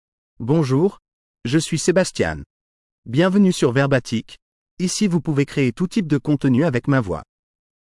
MaleFrench (France)
Sebastian is a male AI voice for French (France).
Voice sample
Male
Sebastian delivers clear pronunciation with authentic France French intonation, making your content sound professionally produced.